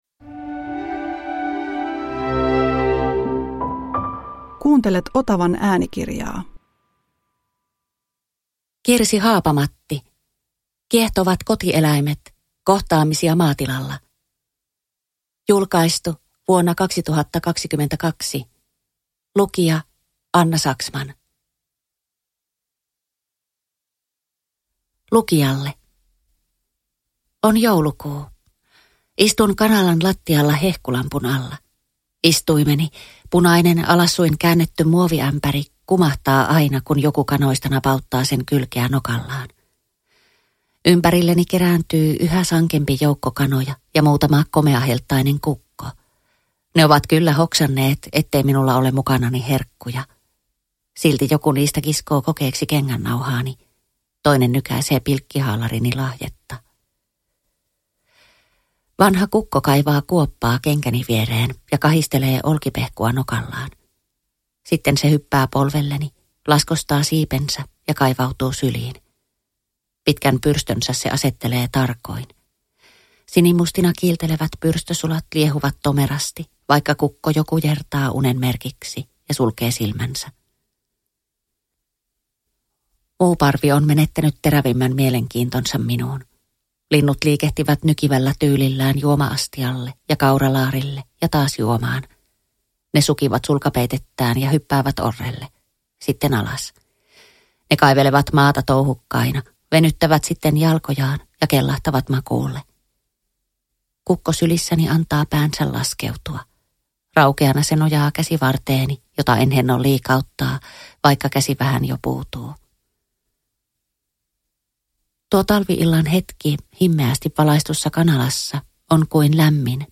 Kiehtovat kotieläimet – Ljudbok – Laddas ner